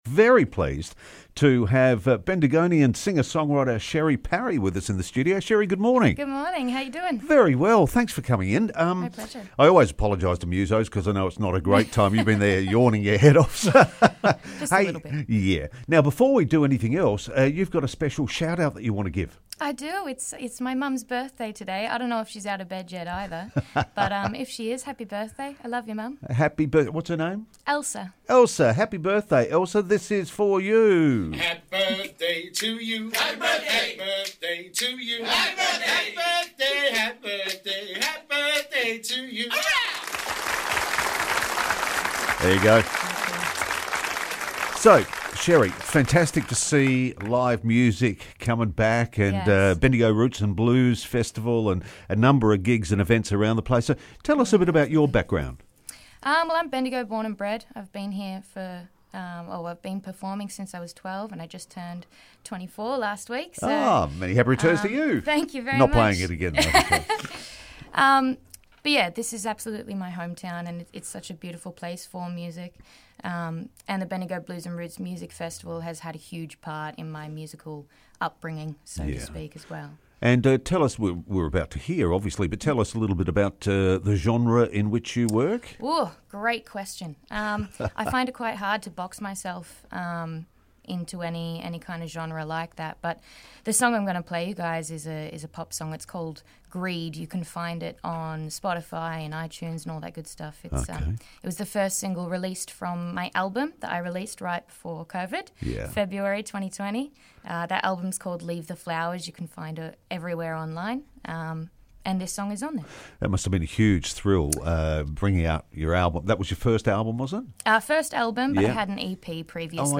called into the studio to perform one of her songs live